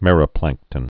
(mĕrə-plăngktən)